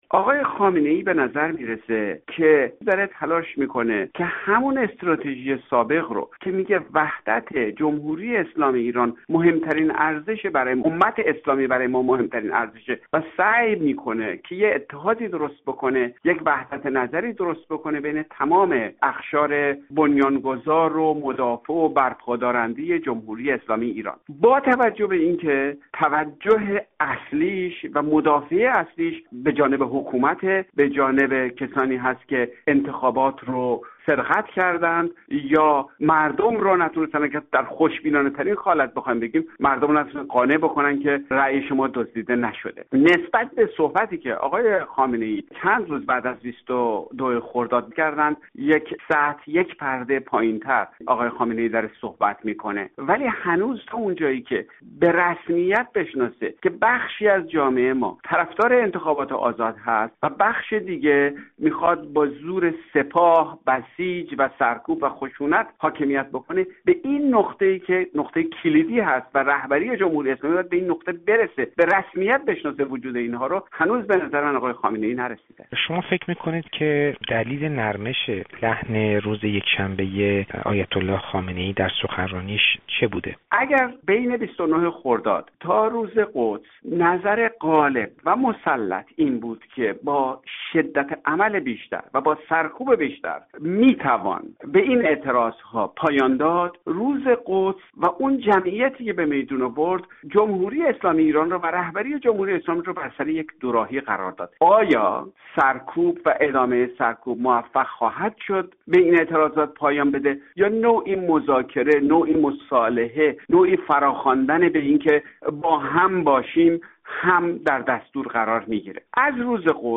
«نرمش نسبی» گفت‌گوی